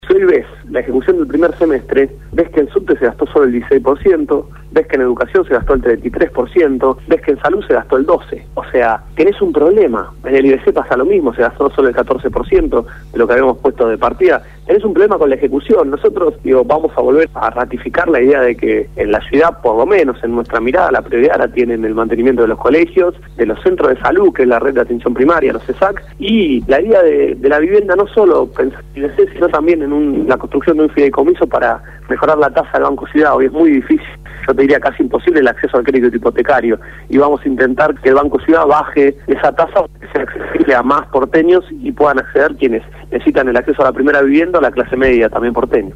Gonzalo Ruanova, Diputado de la Ciudad de Buenos Aires por el Partido Nuevo Encuentro, habló sobre este tema en el programa “Desde el barrio” de Radio Gráfica FM 89.3